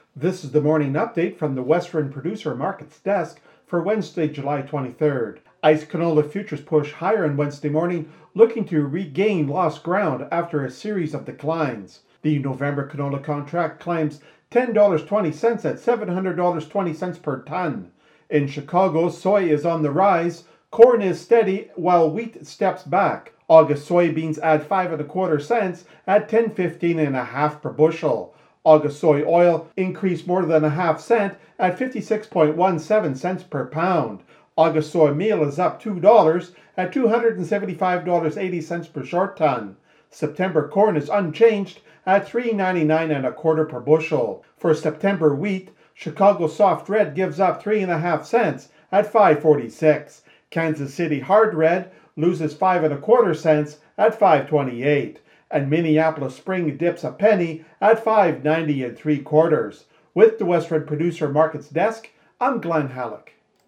MarketsFarm's radio show is delivered twice per day - at noon and at the close of markets - and contains the latest information on the price of canola, wheat, soybeans, corn and specialty crops.